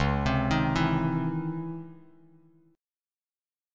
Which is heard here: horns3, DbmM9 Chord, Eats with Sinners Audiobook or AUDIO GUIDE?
DbmM9 Chord